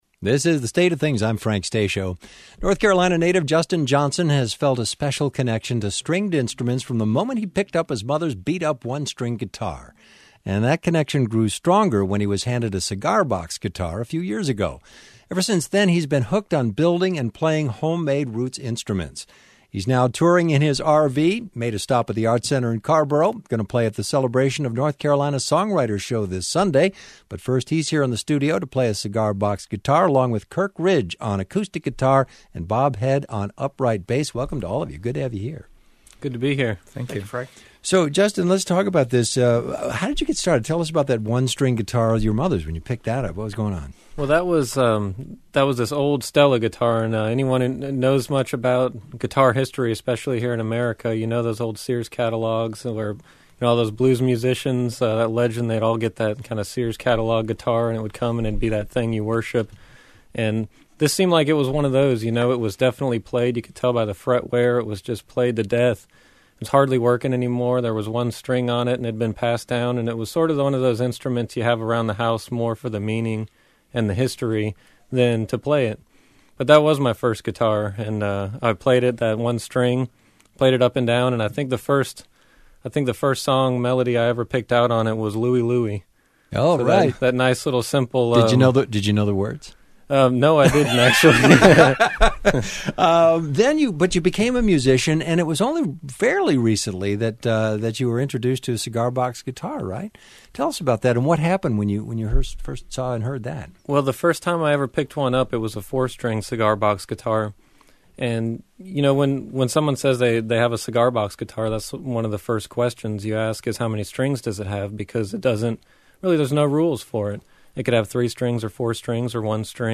conversation and jam